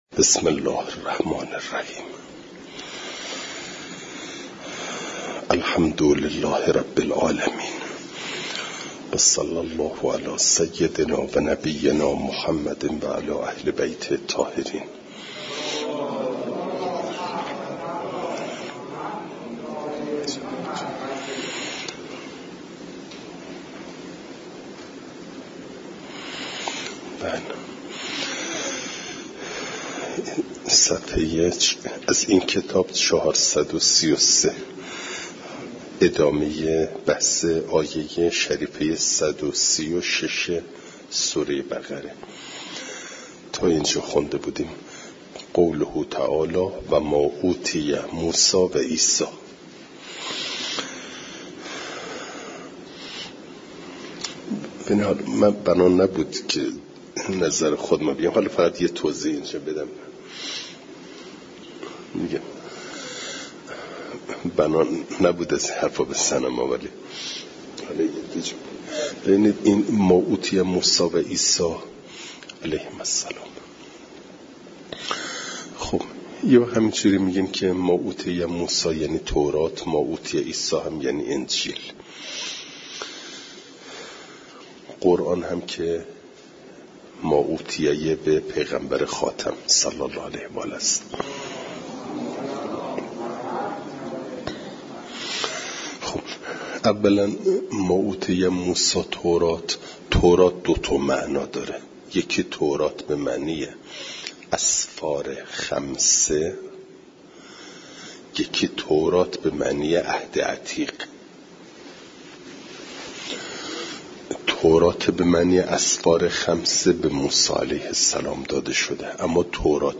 فایل صوتی جلسه صد و سی و ششم درس تفسیر مجمع البیان